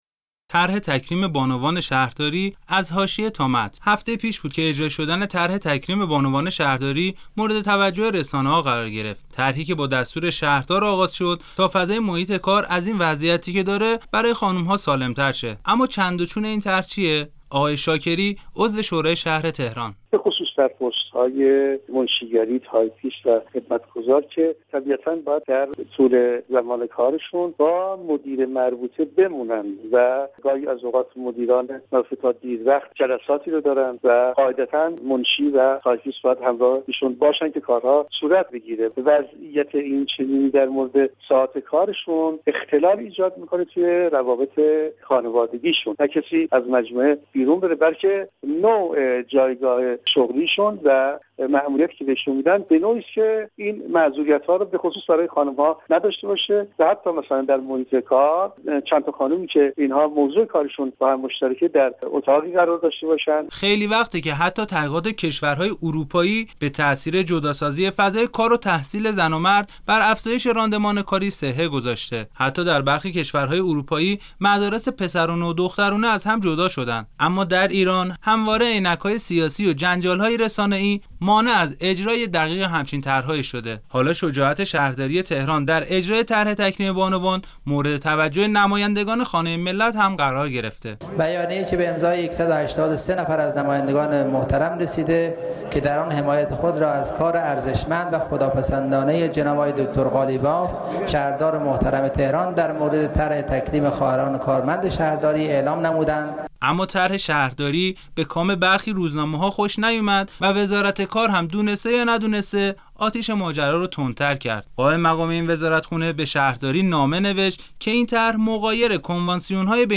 گزارش "شنیدنی"؛ طرح تکریم بانوان شهرداری از حاشیه تا متن - تسنیم